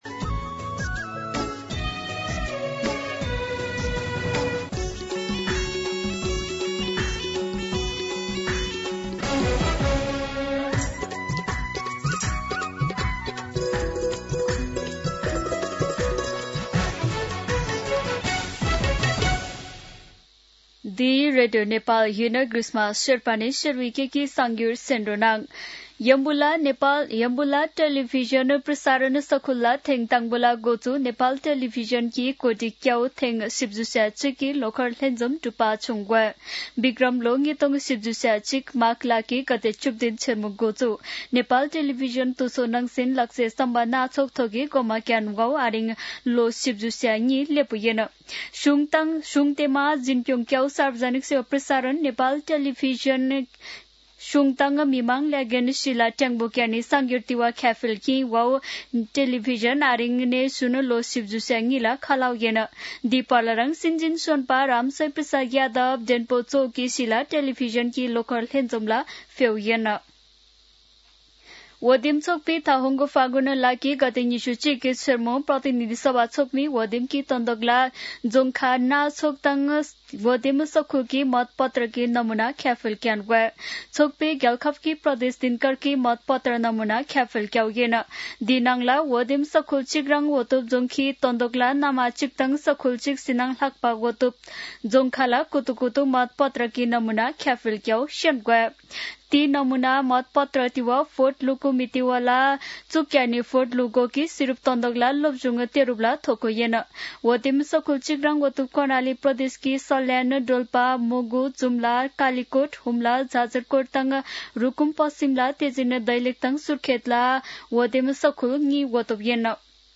शेर्पा भाषाको समाचार : १७ माघ , २०८२
Sherpa-News-10-17.mp3